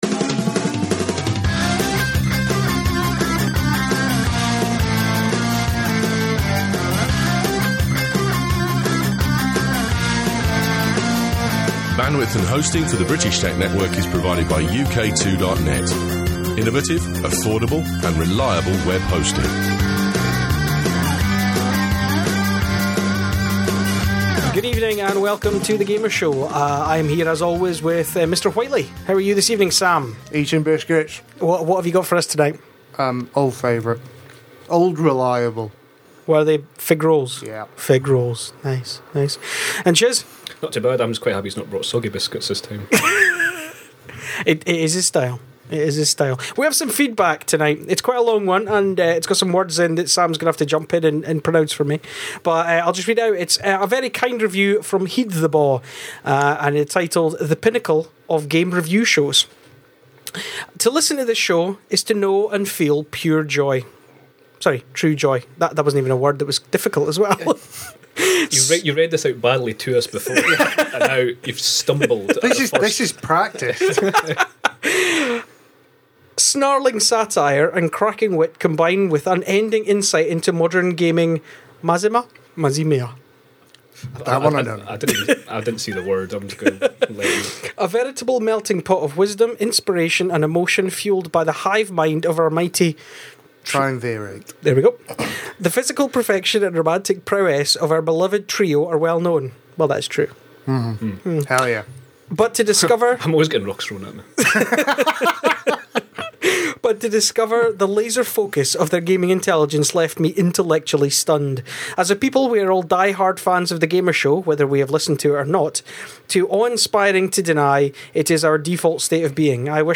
The guys discuss the current state of the Video Games industry and downloadable content (DLC), if Windows 8 will in fact be a catastrophe for PC gaming, the rumours of the new 'Super Slim' PS3 and of course the wonder that is the bargain basement challenge.